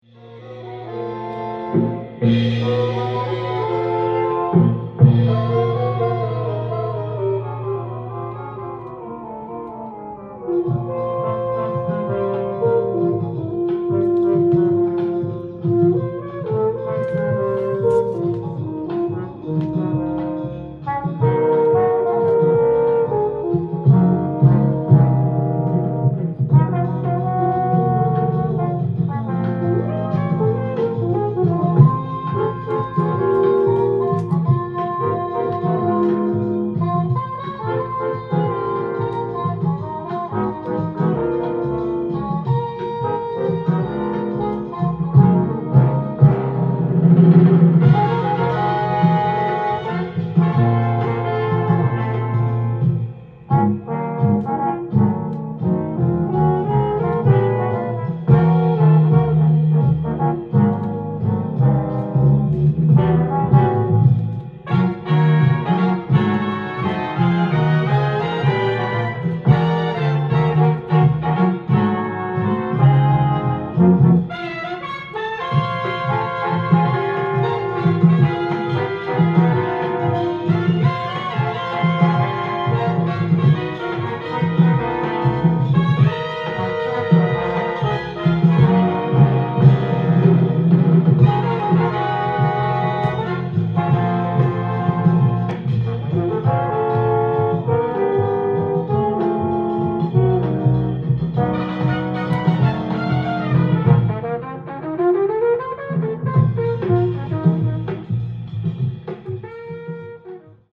店頭で録音した音源の為、多少の外部音や音質の悪さはございますが、サンプルとしてご視聴ください。